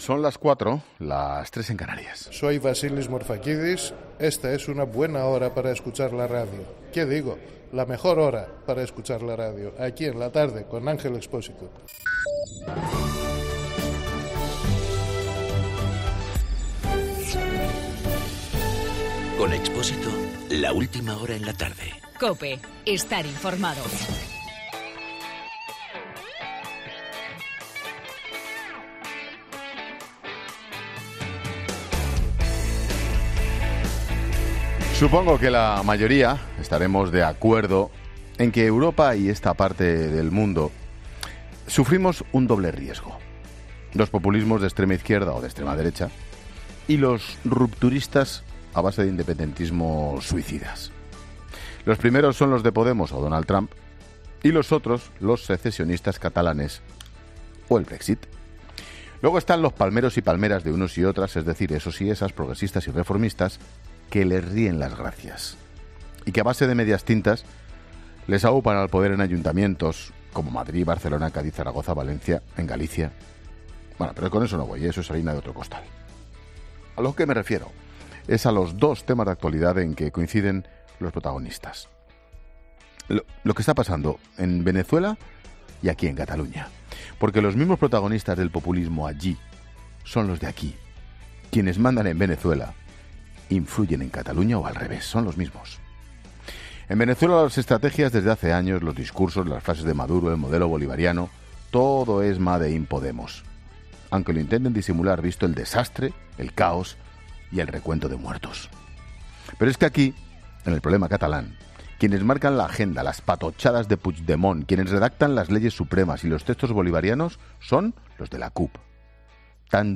AUDIO: Monólogo de Ángel Expósito a las 16h.